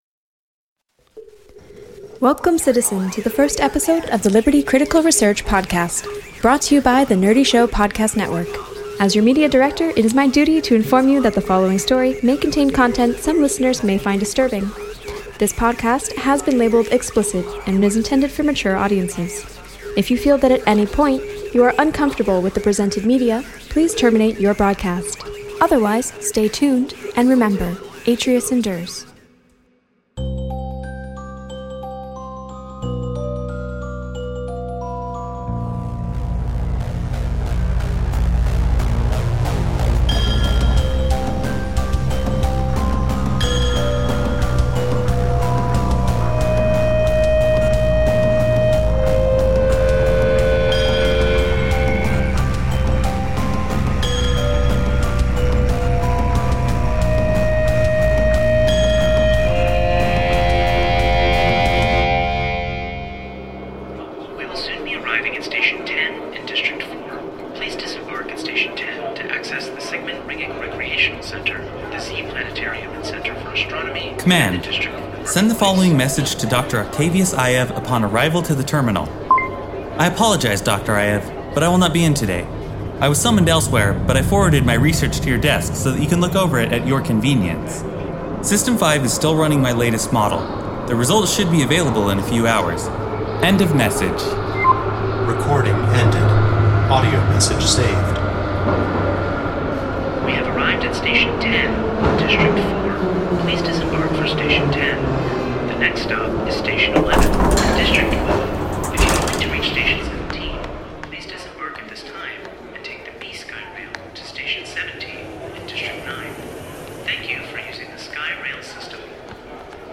This sci-fi audio drama marks the debut of the first scripted series on Nerdy Show and will join our fan-favorite RPG series and other narrative adventures in our new feed Nerdy Show Theatre!